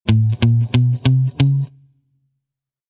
Descarga de Sonidos mp3 Gratis: guitarra 14.